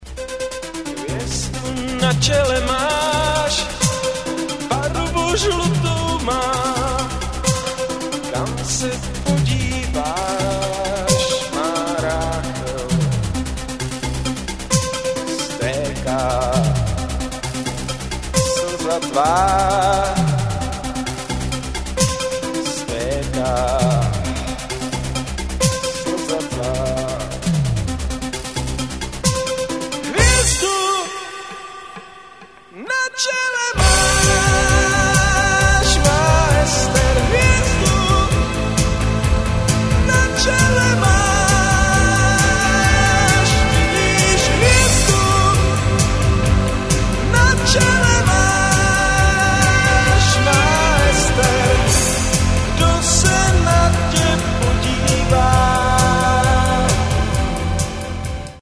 voc